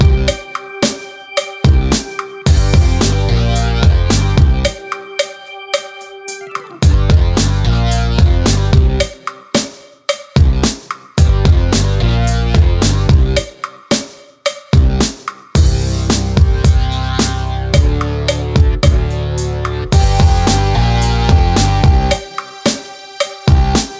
Minus Main Guitar Pop (2000s) 4:01 Buy £1.50